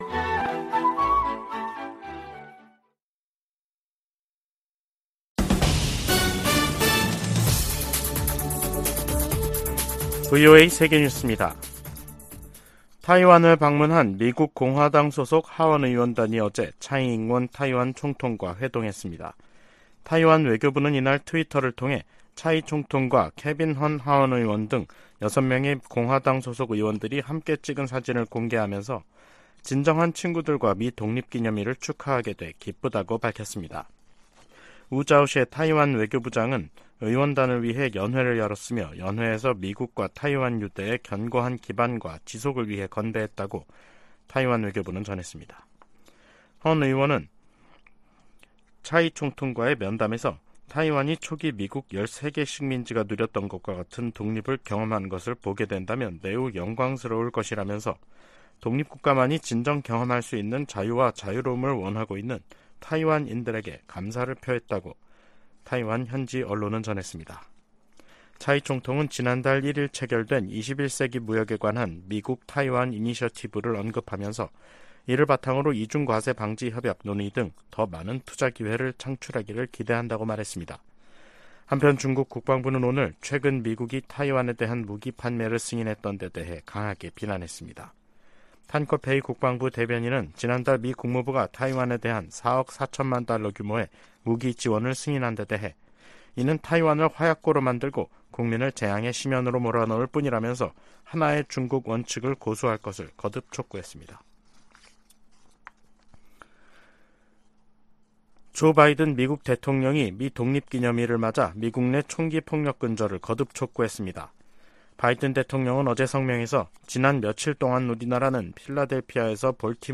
VOA 한국어 간판 뉴스 프로그램 '뉴스 투데이', 2023년 7월 5일 2부 방송입니다.